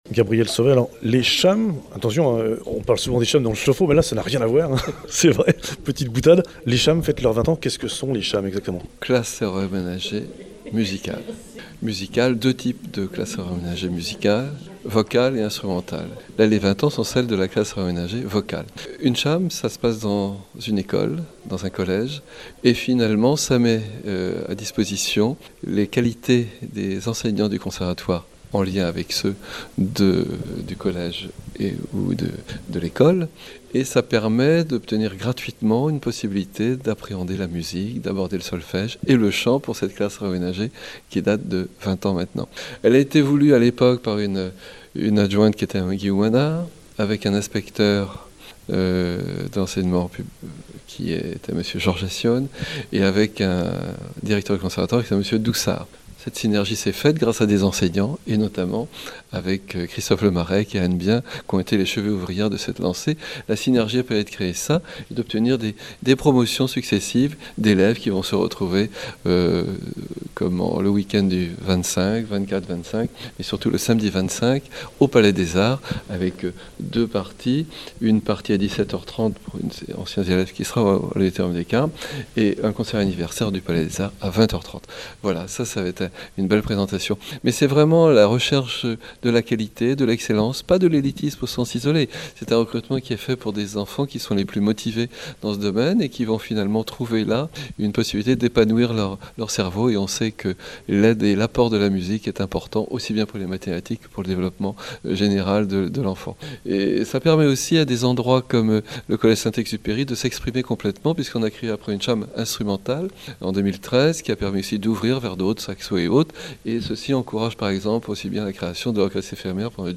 Présentation de la CHAM et son histoire par Gabriel Sauvet  Maire Adjoint à la Culture à Vannes